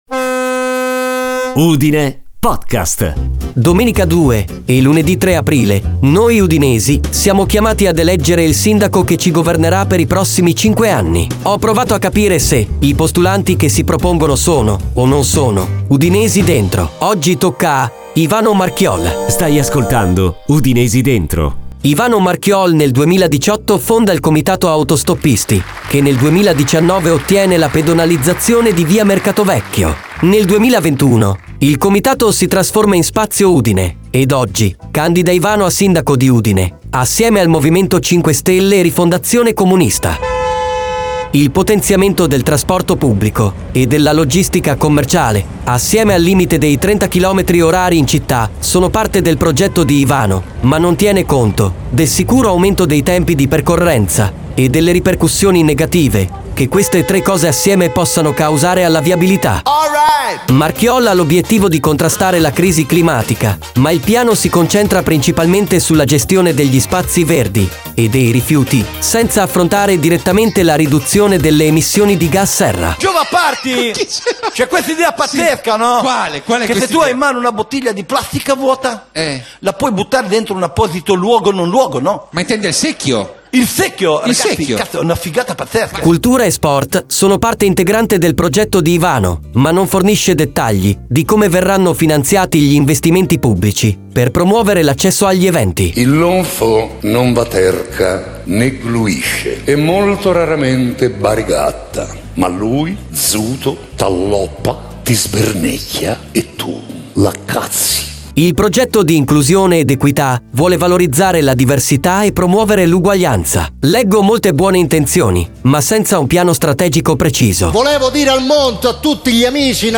Gigi Proietti – L’addetto culturale
Corrado Guzzanti – Quelo
la voce sintetica di Vittorio
la post produzione e il sound design